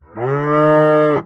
animalia_cow_death.ogg